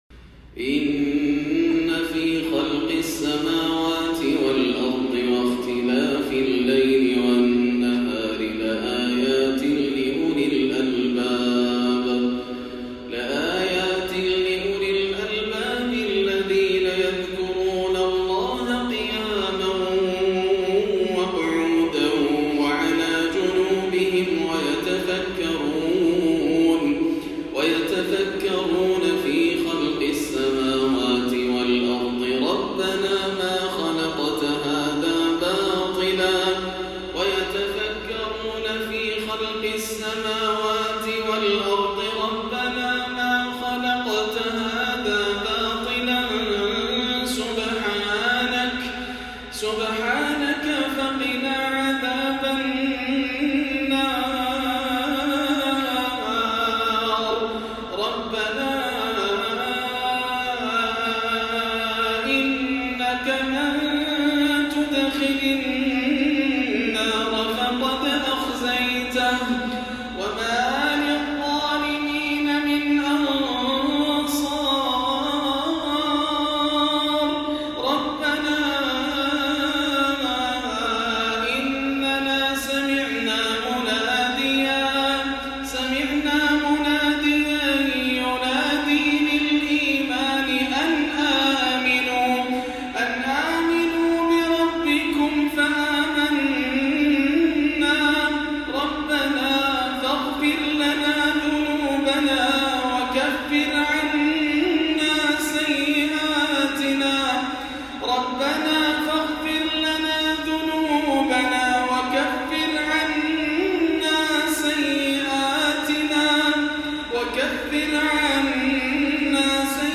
أواخر آل عمران والفرقان من جامع الجفالي بجازان - الثلاثاء 8-8 > عام 1439 > الفروض - تلاوات ياسر الدوسري